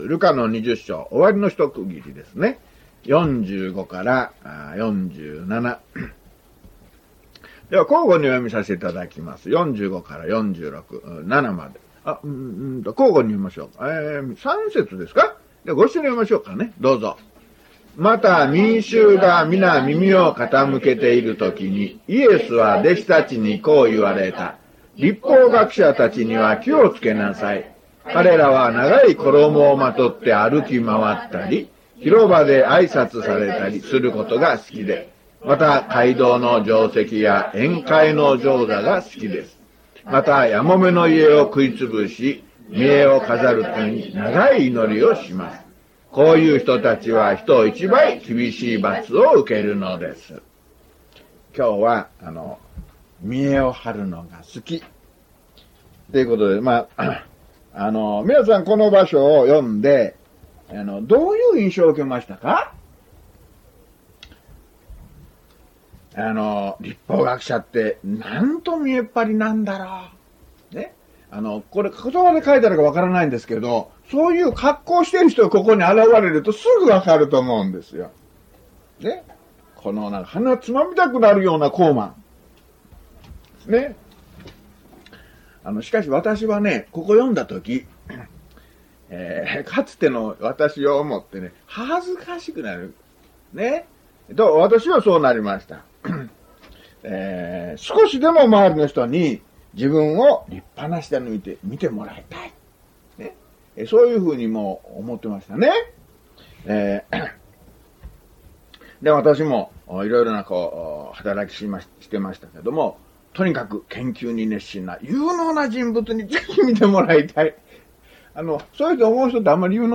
luke158mono.mp3